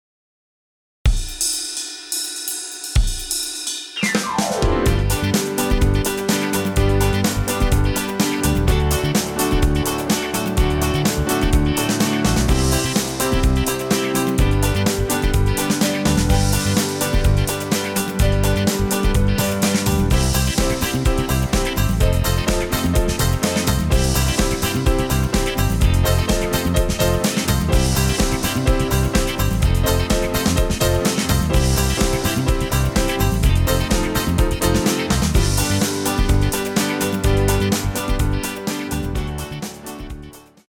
Rhythm Track